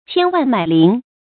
注音：ㄑㄧㄢ ㄨㄢˋ ㄇㄞˇ ㄌㄧㄣˊ
千萬買鄰的讀法